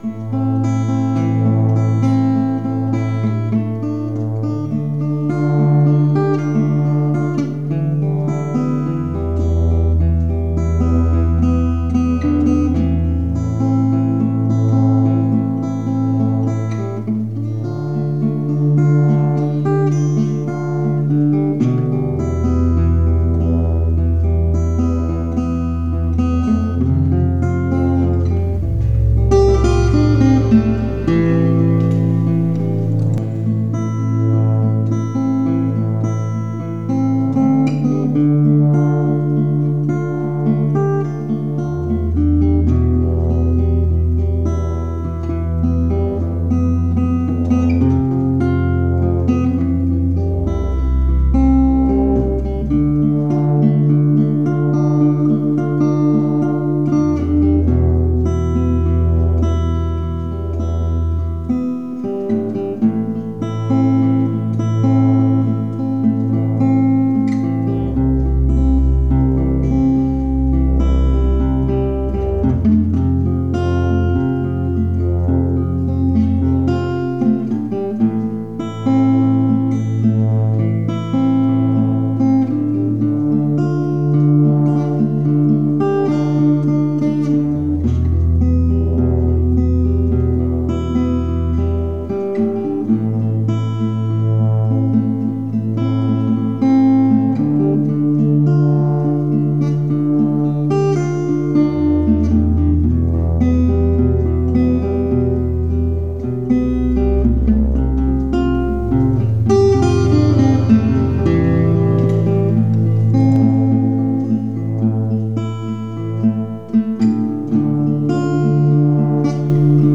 Musique Lo-FI
composition instrumentale minimaliste et enveloppante